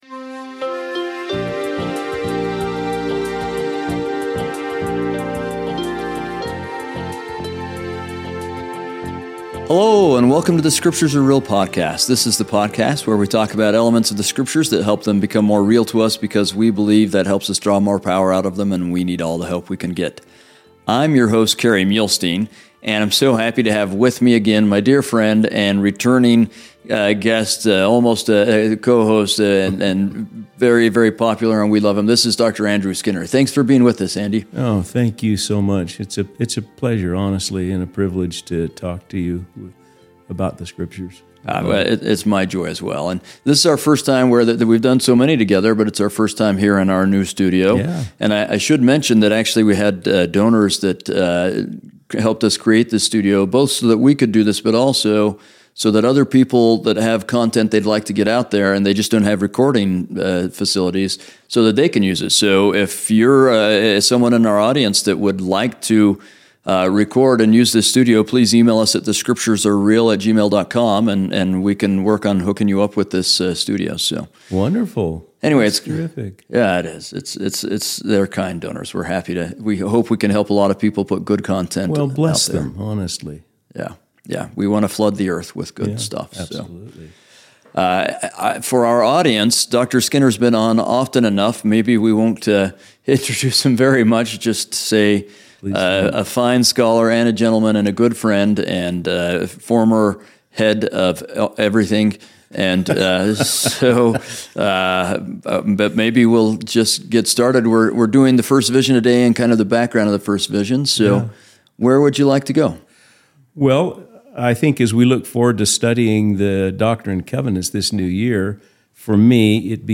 We interview both experts (people with language, archaeological, historical backgrounds, etc.), and lay folks, and explore times when the scriptures became real to them. This is done from the viewpoint of members of the Church of Jesus Christ of Latter-day Saints.